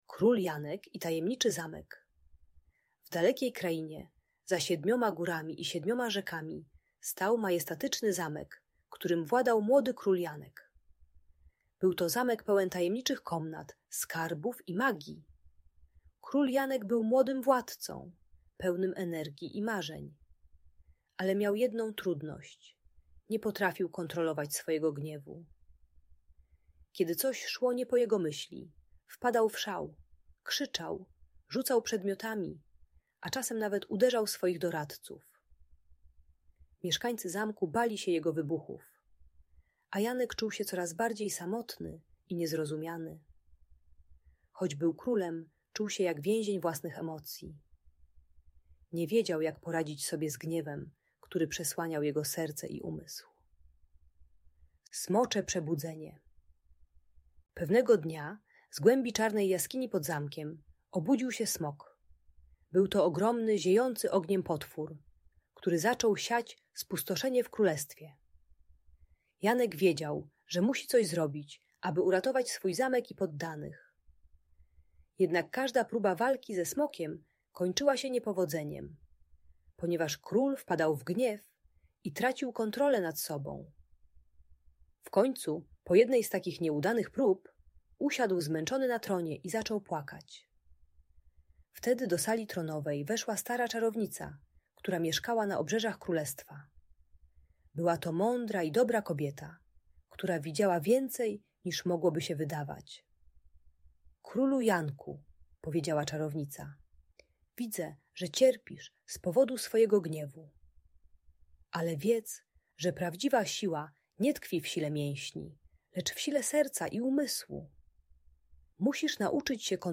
Król Janek i Tajemniczy Zamek - Bunt i wybuchy złości | Audiobajka